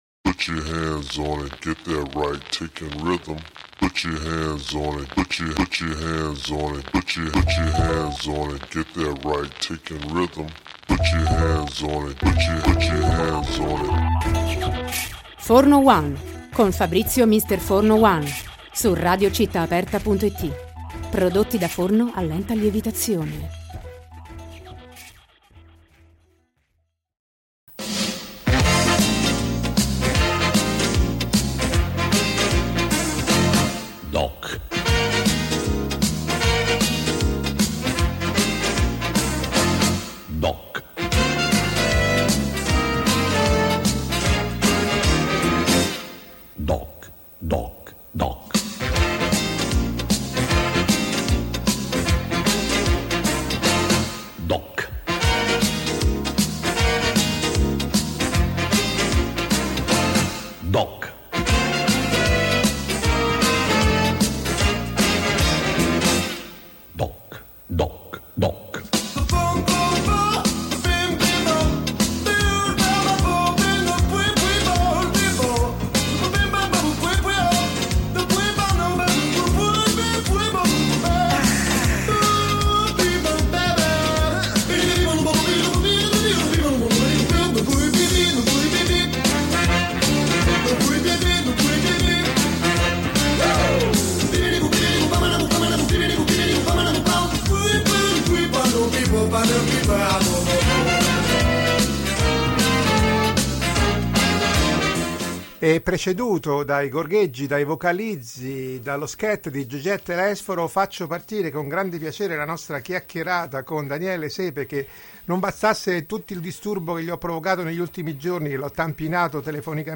Ascolti DOC intervista Daniele Sepe